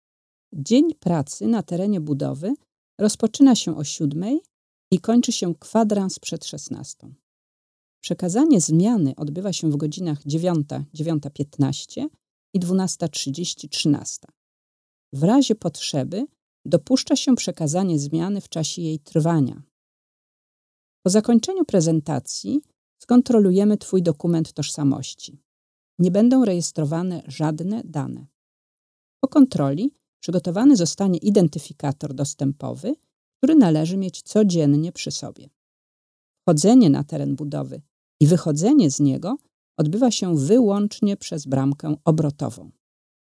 De voicing wordt per dia opgenomen in onze eigen studio. Opvolgend wordt de audio bewerkt zodat het goed te volgen is, ook in een drukke bouwkeet.
• Voice-over veiligheidsinstructies